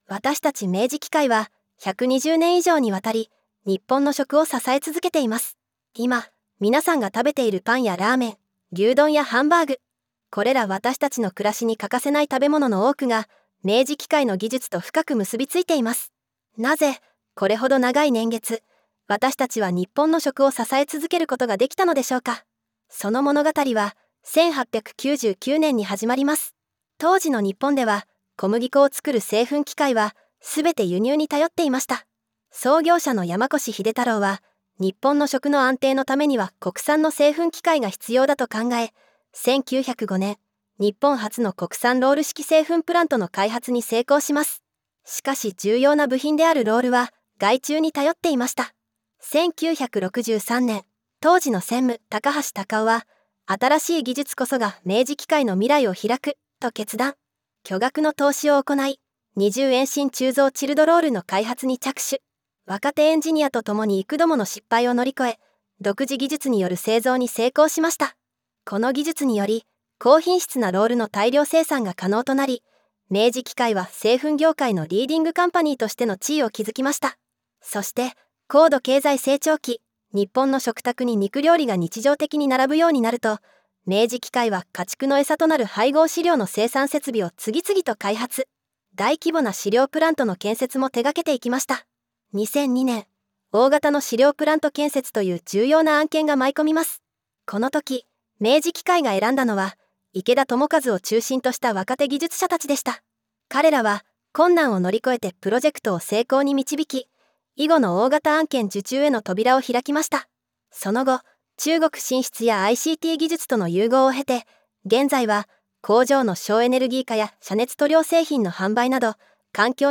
【ナレーション音声 女性2】var.2
明治機械_再校ナレーションv2_女性2_01.mp3